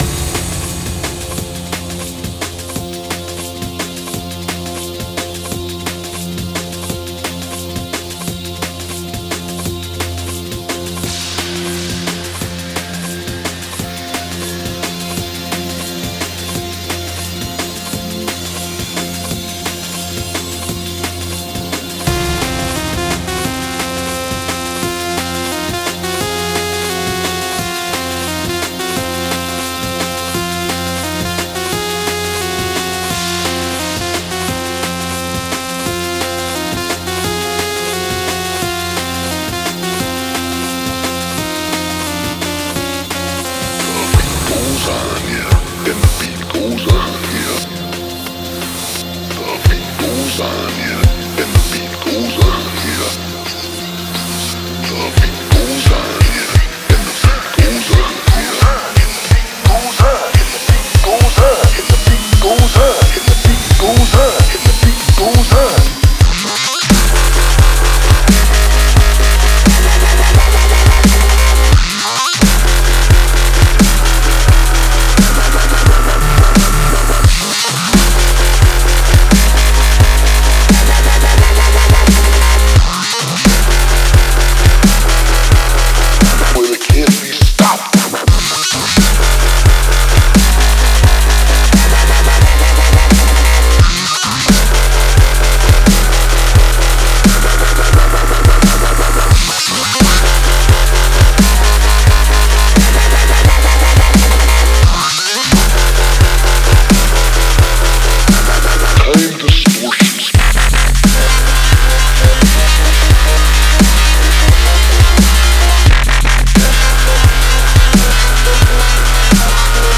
Категория: Dub step